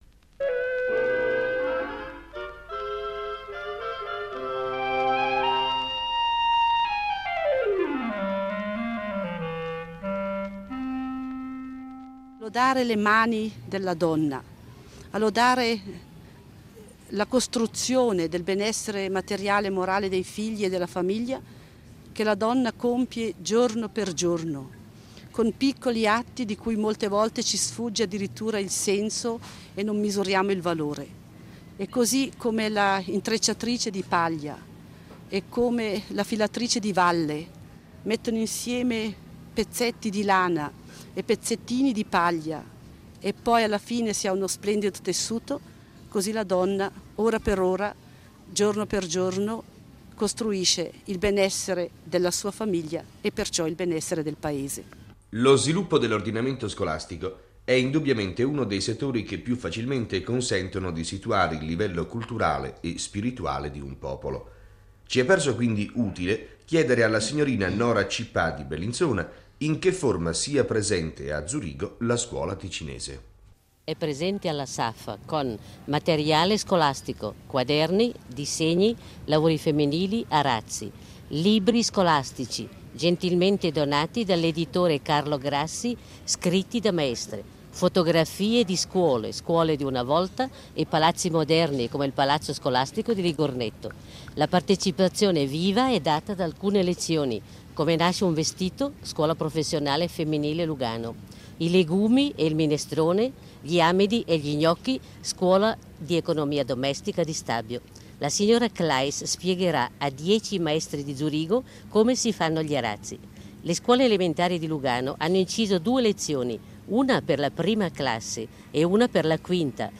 Archivi RSI